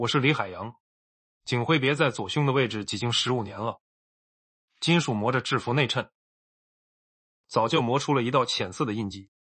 Voix Off de Narration Cinématographique : Narration IA Professionnelle pour le Cinéma
Rythme Atmosphérique
Narration de Film